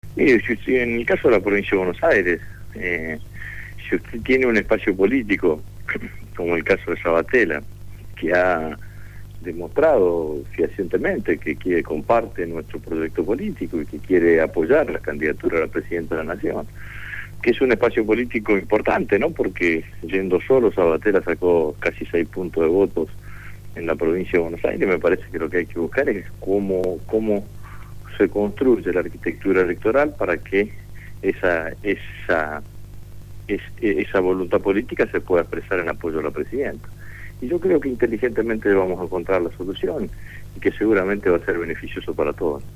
Lo dijo el Diputado Nacional Agustín Rossi, entrevistado en el programa «Punto de partida» de Radio Gráfica también reflexionó sobre el discurso de la presidenta en la apertura de sesiones en el Congreso Nacional; «El disurso de la presidenta fue muy contundente, el país tiene  crecimiento económico, inclusión social, niveles record de reservas, superhabit de la balanza comercial y un nivel de prestación social como nunca antes había tenido, una argentina totalmente distinta a la del 2003» señaló.